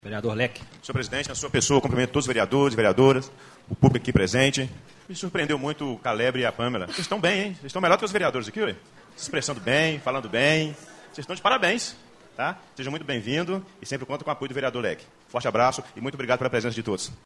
O vereador Alexandre Barros, resumiu em poucas palavras a presença dos jovens.